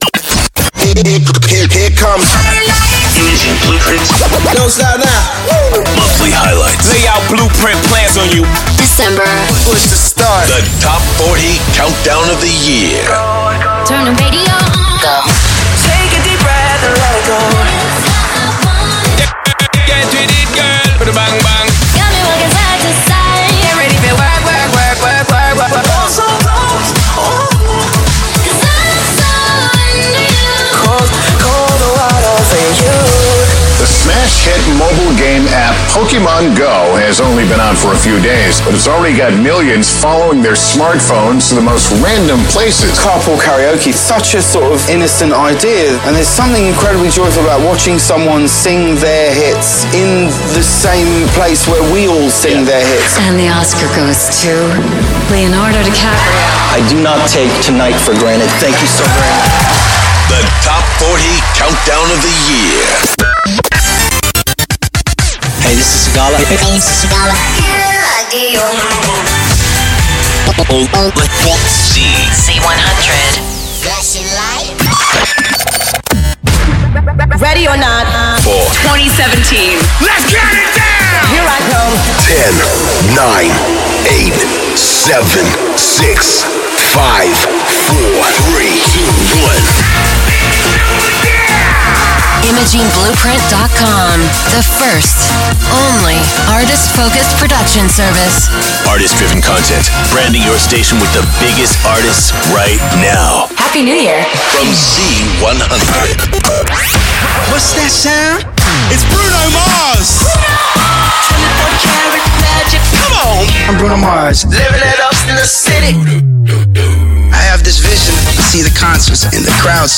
Our client KIIS is used to demonstrate 'IB' production alongside the world famous Z100.
It features an innovative, online database containing thousands of audio files including sound design, music beds, artist-imaging workparts, vocal work parts, topical elements and much much more!